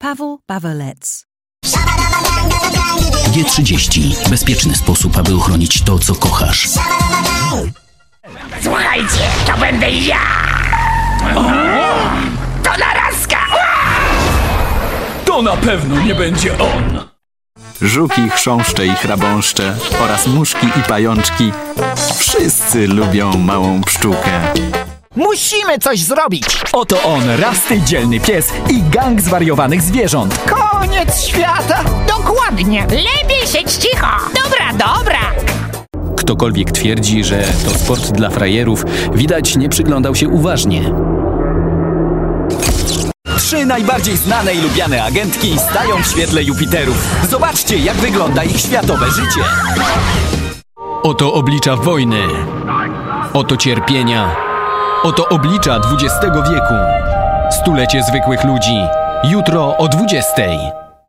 Polish voiceover artist